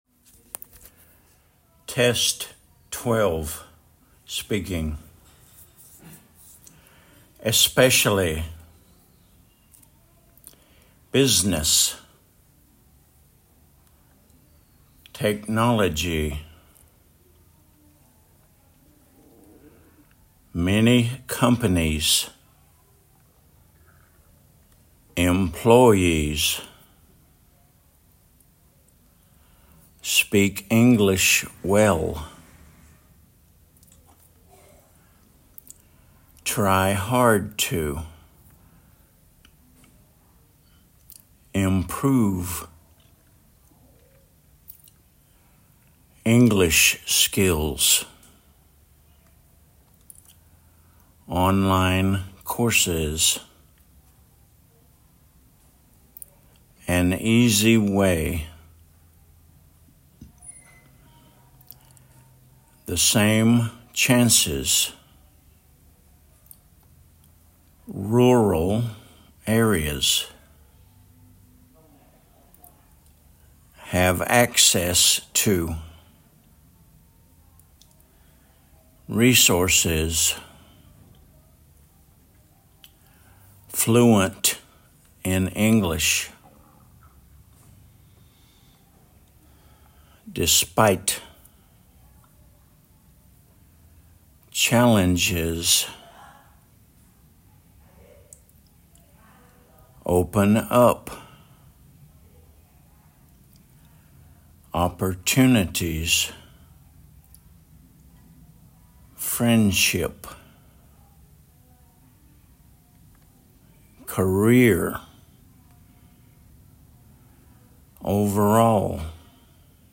especially /ɪˈspɛʃəli/
technology /tɛkˈnɒləʤi/
fluent in English /ˈfluːənt ɪn ˈɪŋɡlɪʃ/
opportunities /ˌɒpəˈtjuːnɪtɪz/